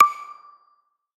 pause-retry-click.ogg